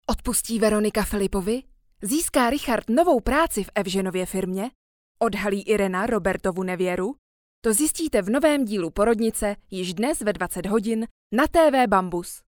Profesionální český ženský voiceover
profesionální zvuk a vysoká technická kvalita
ukazka-tvserial.mp3